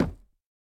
Minecraft Version Minecraft Version latest Latest Release | Latest Snapshot latest / assets / minecraft / sounds / block / chiseled_bookshelf / break5.ogg Compare With Compare With Latest Release | Latest Snapshot